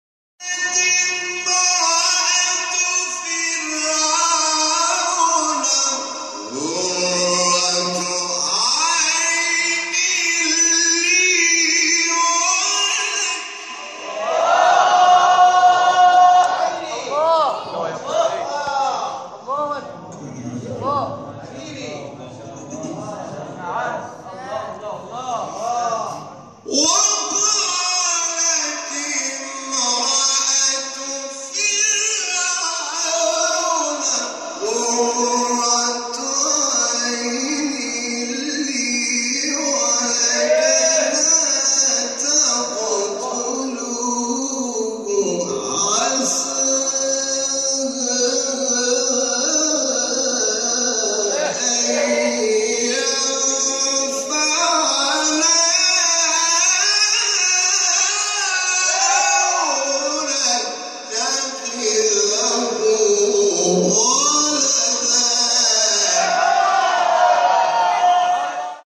آیه 9 سوره قصص استاد حامد شاکرنژاد | نغمات قرآن | دانلود تلاوت قرآن